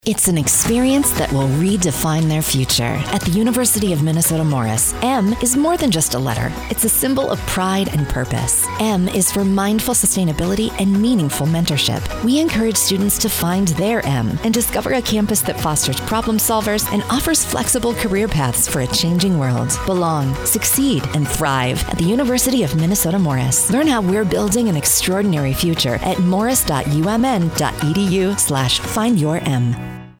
Radio Spot